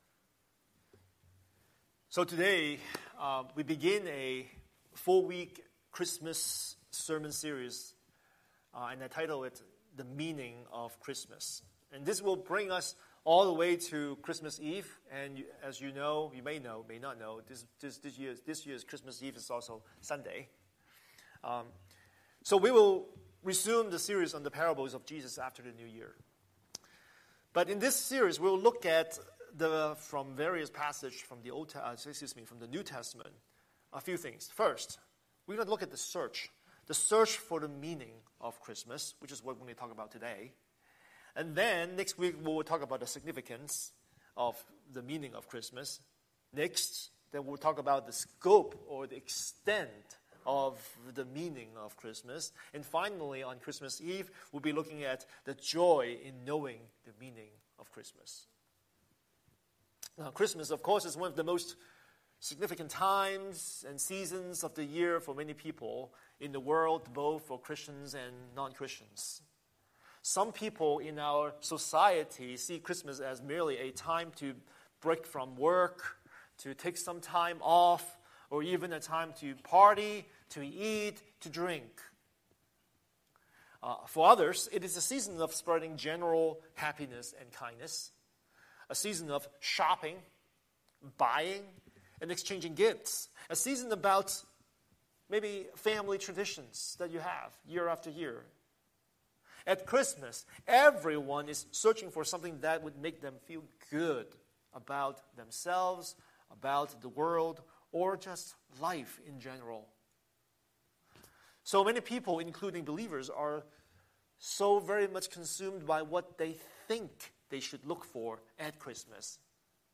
Scripture: Matthew 2:1–12 Series: Sunday Sermon